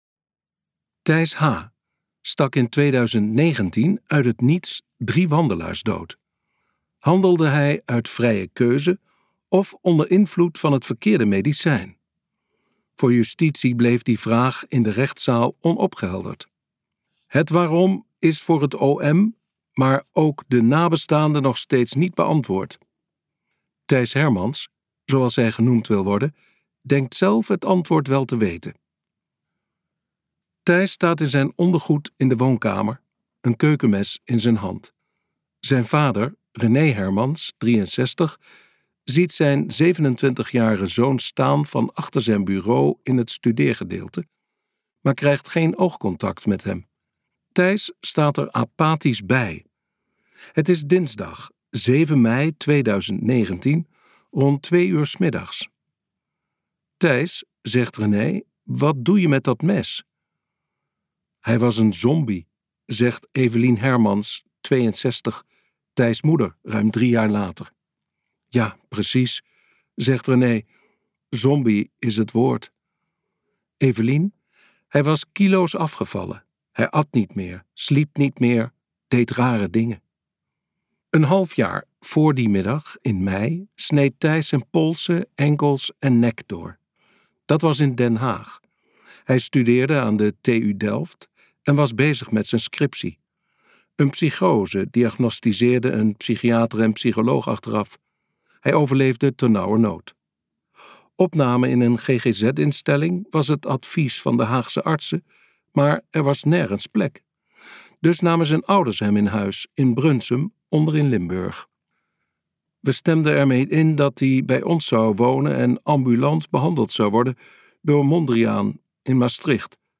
Ambo|Anthos uitgevers - Dodelijke zorg luisterboek